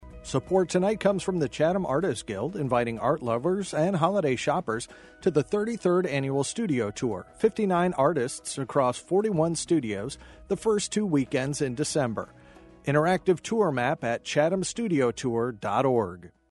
Chatham-Studio-Tour-Radio-Spot.mp3